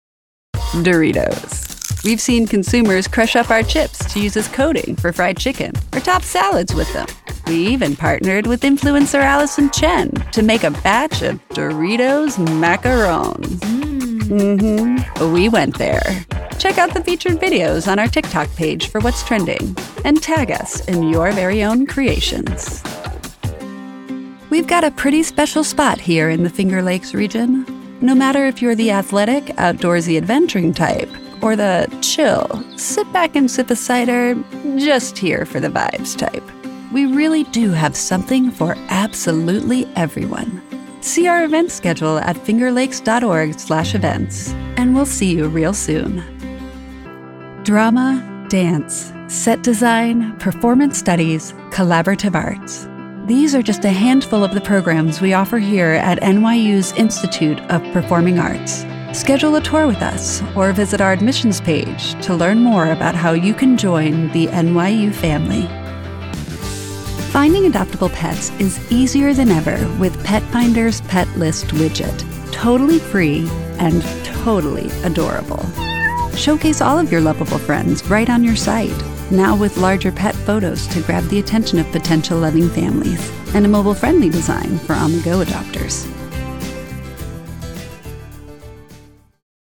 Anglais (Américain)
Naturelle, Cool, Accessible, Polyvalente, Chaude
Vidéo explicative